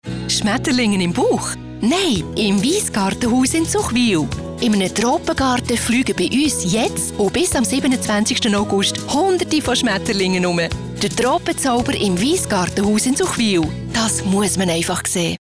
Werbung Schweizerdeutsch (BE)
Sprecherin mit breitem Einsatzspektrum.